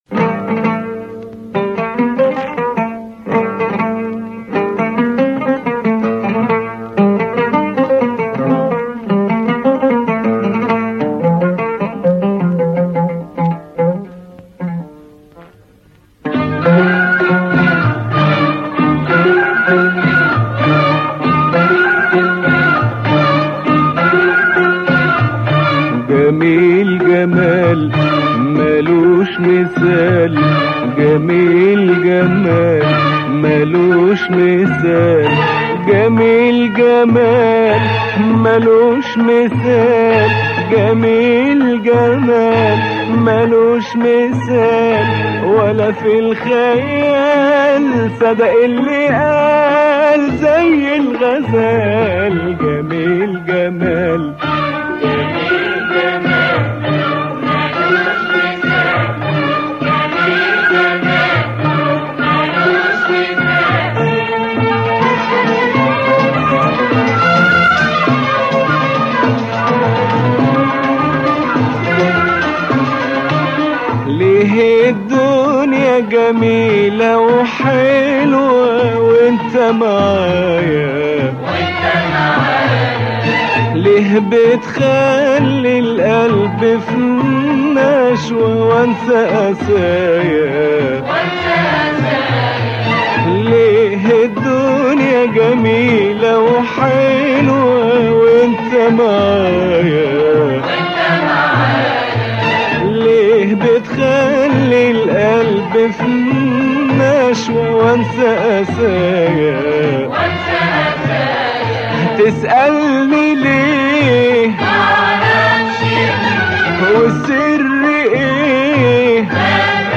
ترانه عربی اغنية عربية